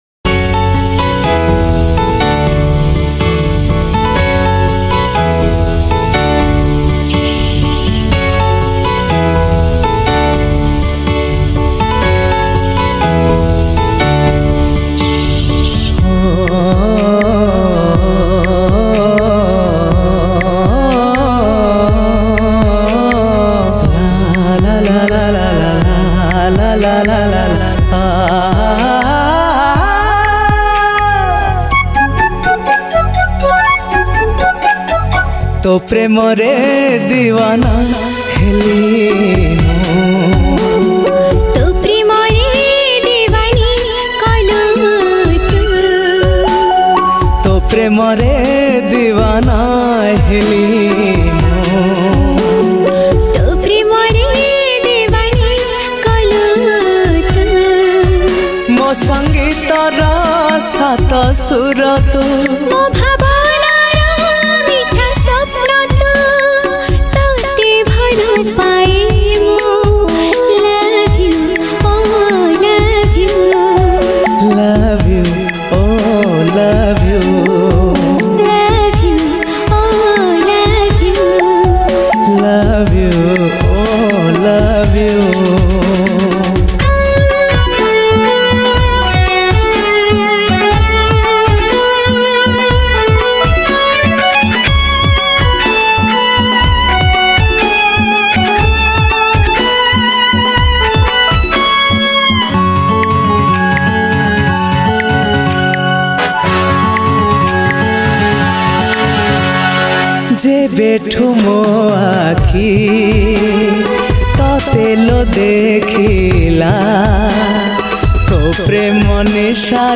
Odia Romantic Song